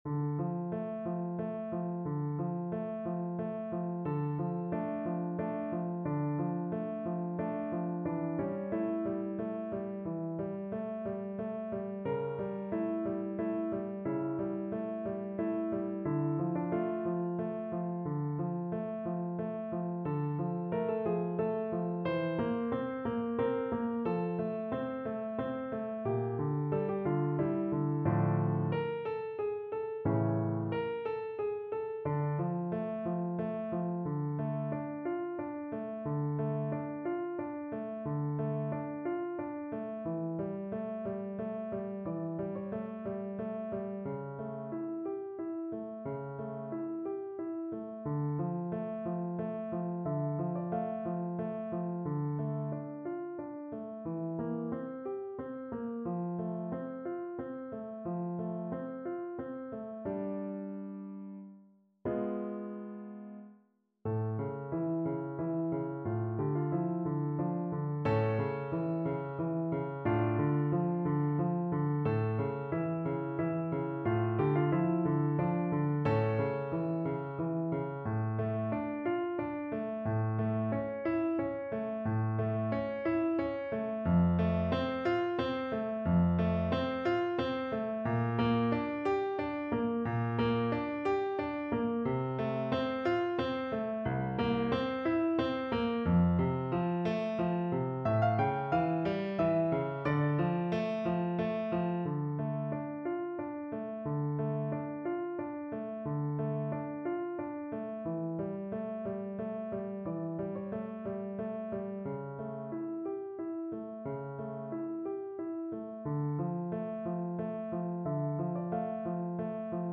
Donizetti: Romanza Nemorina (na flet i fortepian)
Symulacja akompaniamentu